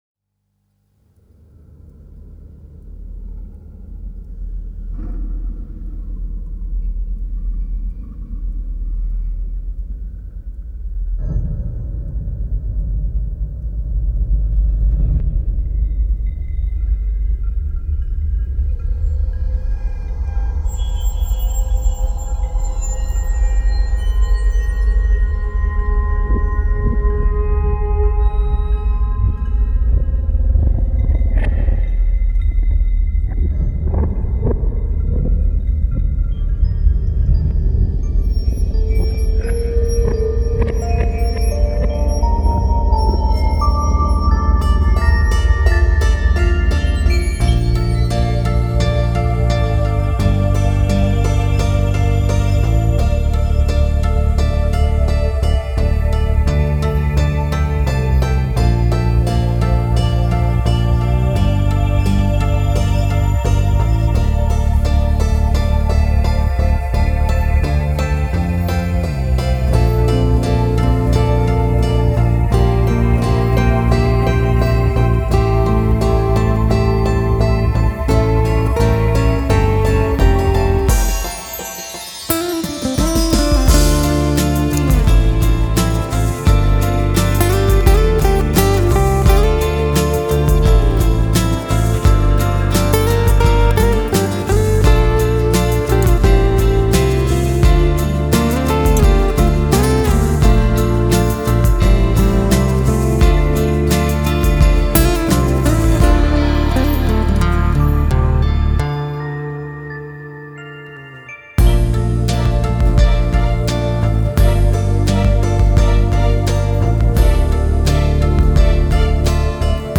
dunkel,
melancholisch
Rap Mittelteil
Tempo 86 4/4 Dm